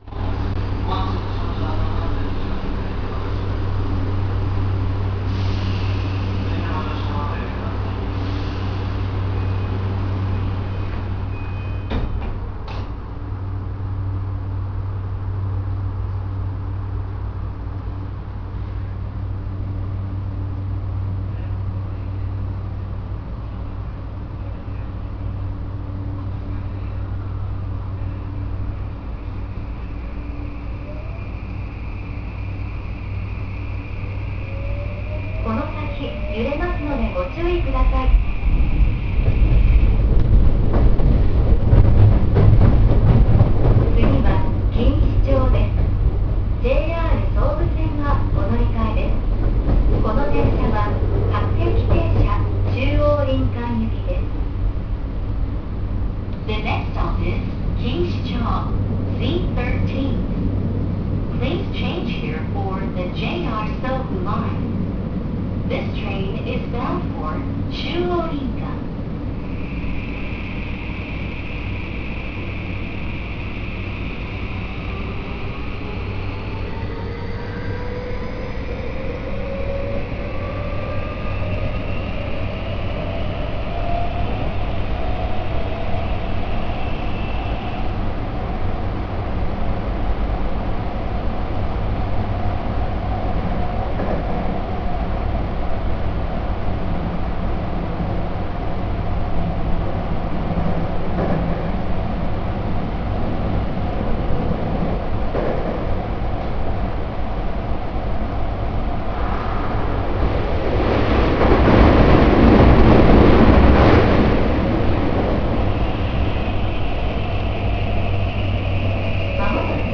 ・2020系走行音
【東京メトロ半蔵門線】押上→錦糸町（2分27秒：801KB）
E235系と同様のSiC-VVVFを採用しています。ドアチャイムも標準的な物に変更されたので、目を閉じているとＪＲの車両に乗っているかのようです。
JR東日本が山手線に登場させたE235系に準じた三菱SiCのVVVFを採用しており、新しい車両ながらその走行音はかなり派手なものになりました。